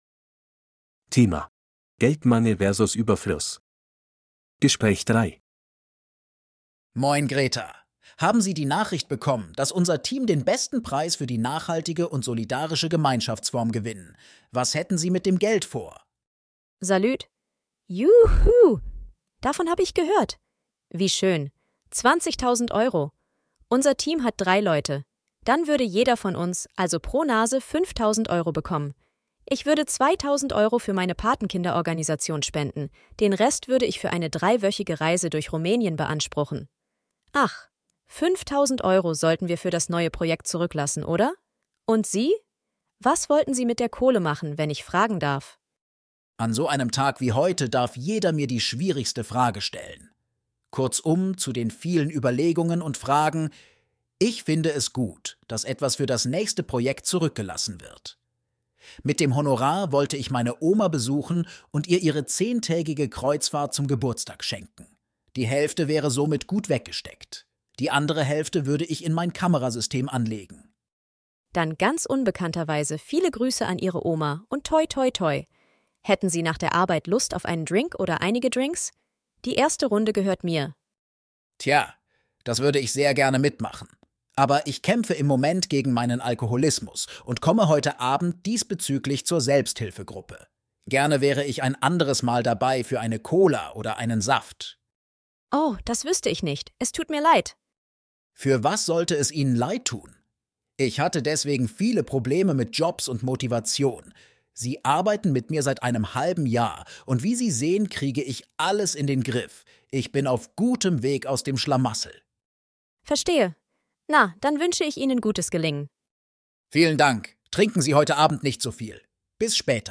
B2-C1-Registeruebung-5-Geldmangel-vs.-Ueberfluss-Gespraech-3.mp3